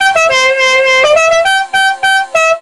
dixie_horn.wav